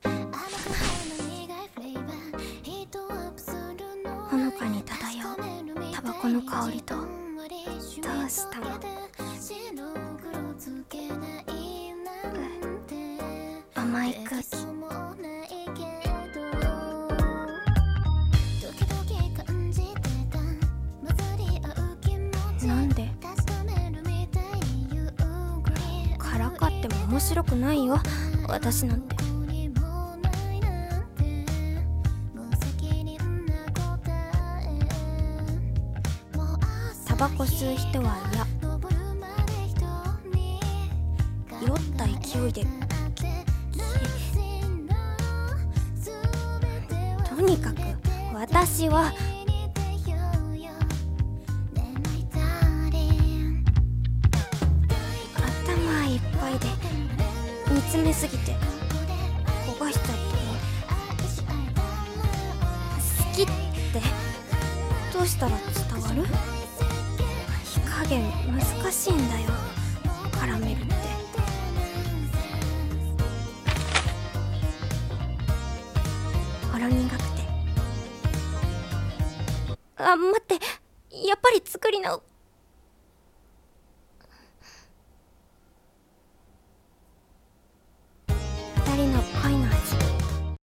【声劇】こがれる、きゃらめりぜ。